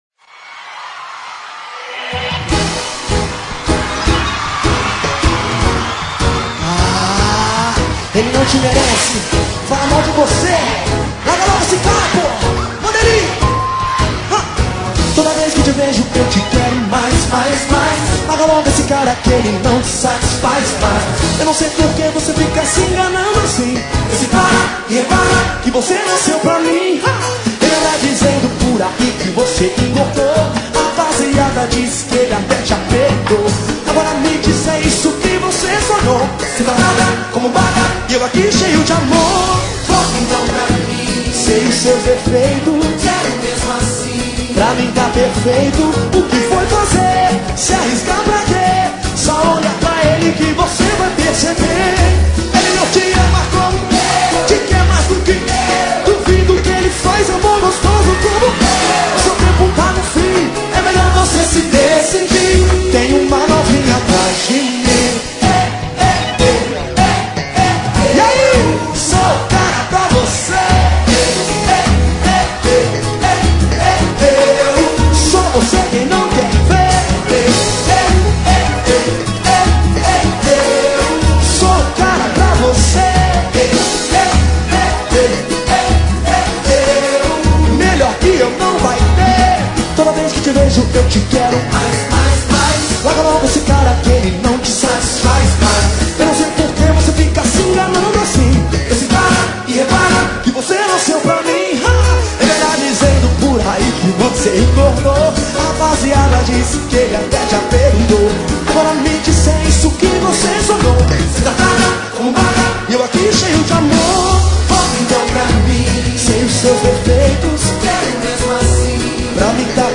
Samba E Pagode